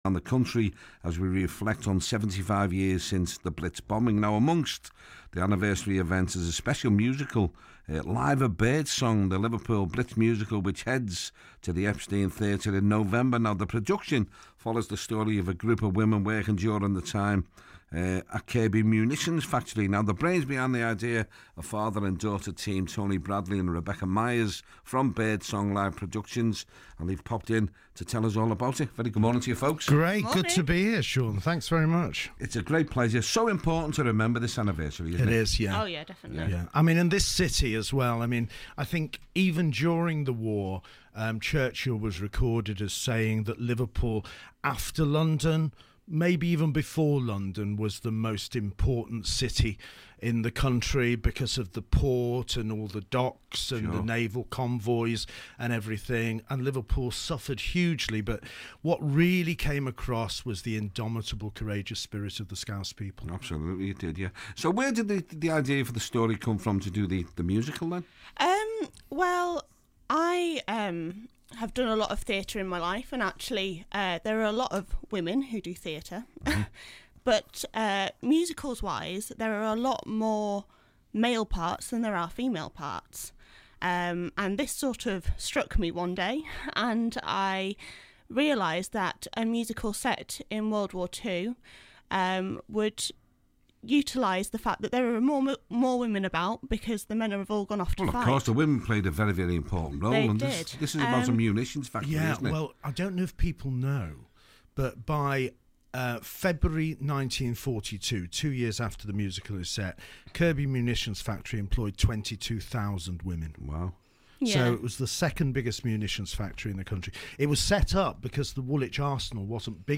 bbc_radio_merseyside-2.mp3